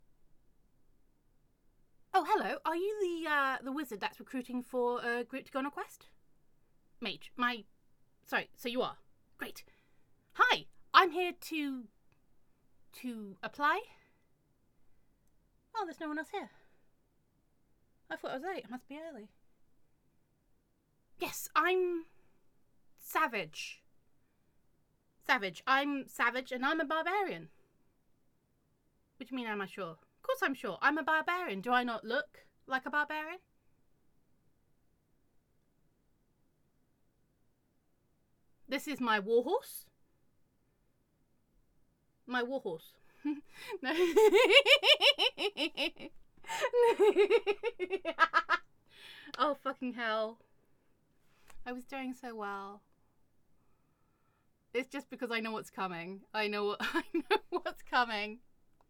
FALSE START.mp3
I love that laugh.